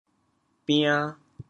“丙”字用潮州话怎么说？
bian2.mp3